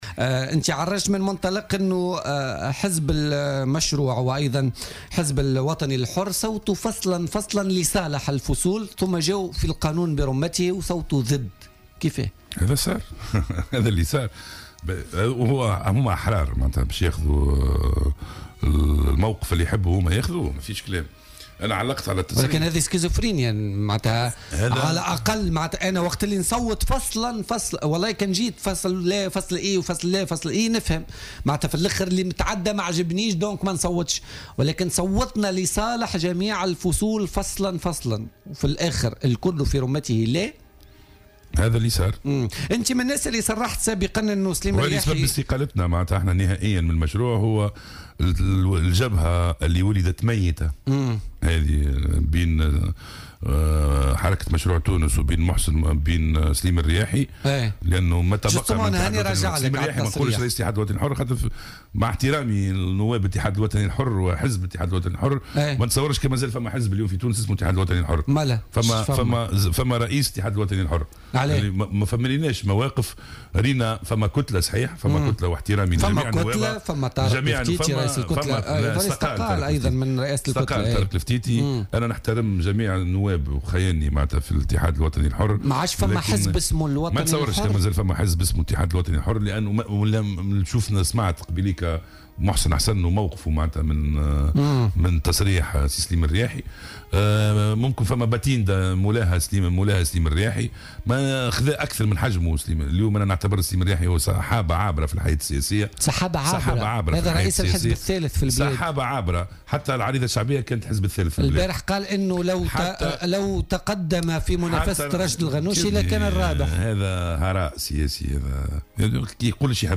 وقال ضيف "بوليتيكا" إنه لم يعد هناك ما يسمى الاتحاد الوطني الحر معتبرا أن الجبهة بين هذا الحزب وحركة مشروع تونس "ولدت ميتة"، بحسب تعبيره.